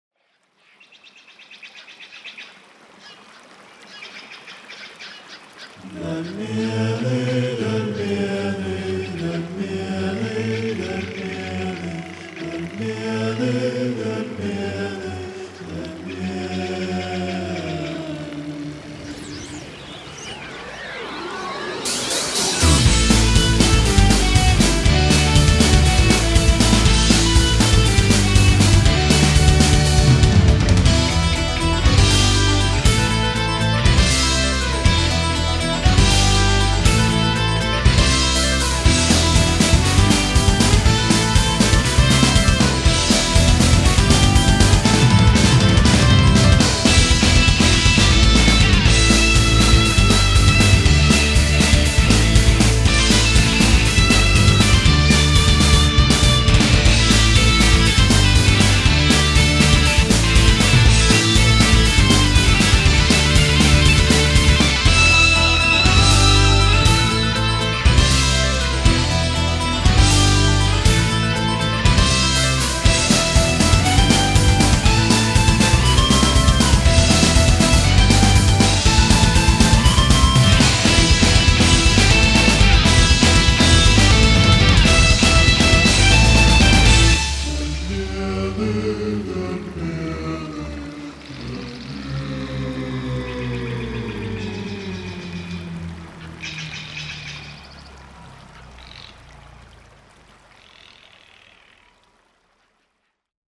Инструментальное путешествие